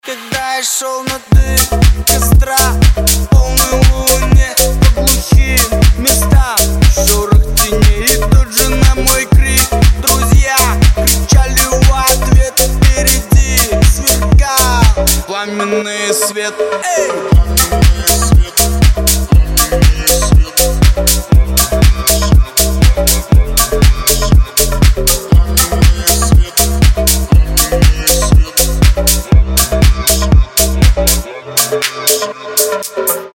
Клубные
Клубный ремикс популярного хита белорусского рэпера!
deep house